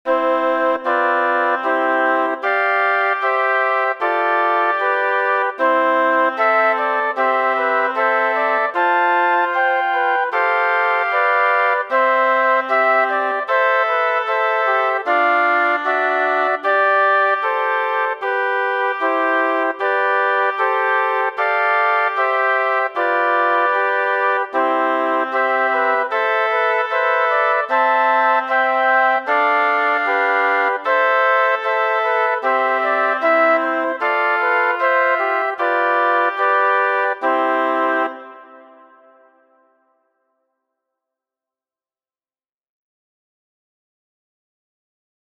Z       Zusammenspiel im Orchester in C-Dur (alle Instrumente)
Flötenfassung -